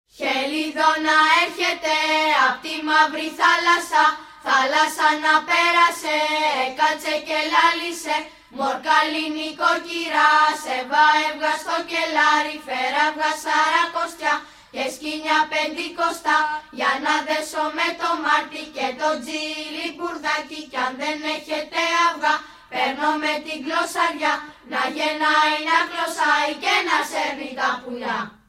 d12_05_sample_helidonisma.mp3